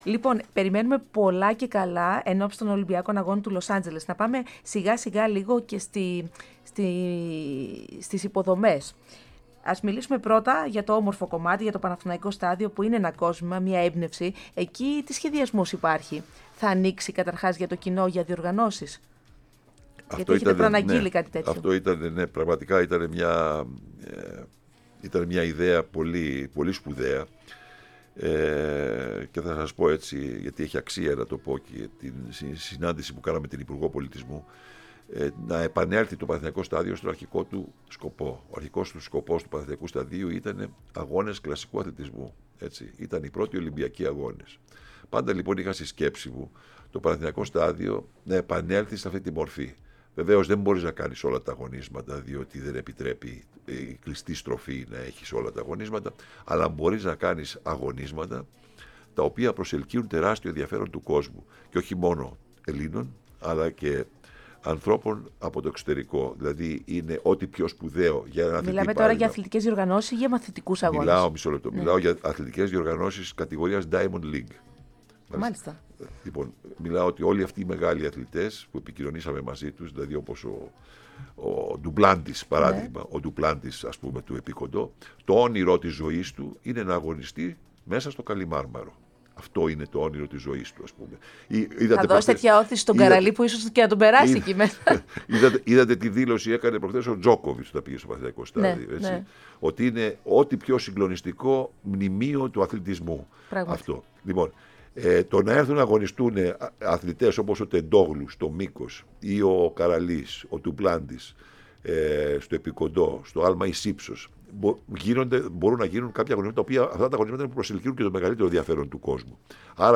Ο πρόεδρος της Ελληνικής Ολυμπιακής Επιτροπής φιλοξενήθηκε στο στούντιο της ΕΡΑ ΣΠΟΡ και συγκεκριμένα στην εκπομπή "Πρόσωπα" και μίλησε για την προσπάθεια εκσυγχρονισμού των αθλητικών εγκαταστάσεων της χώρας, για την καλύτερη δυνατή προετοιμασία των Ελλήνων αθλητών.